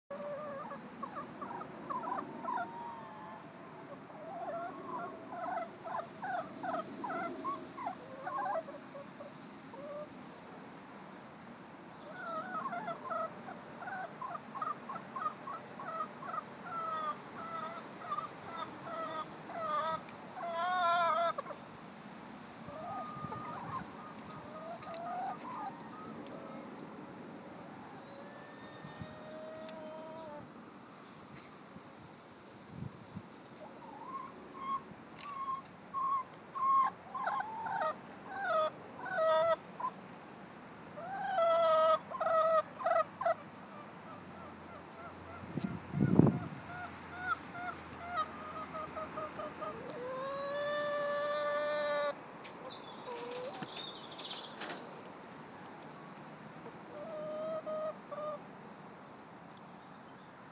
There is some clucking going on and pecking order to be established.